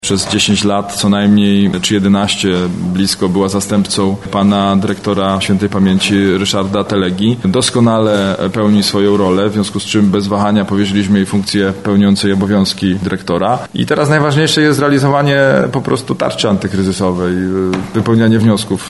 Mówi Piotr Chęciek starosta dębicki.